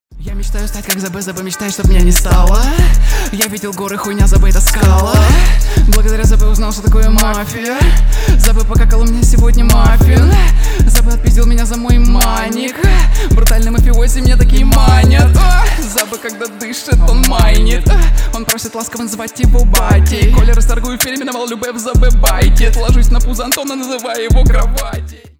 Рэп и Хип Хоп # с басами